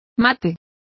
Complete with pronunciation of the translation of matt.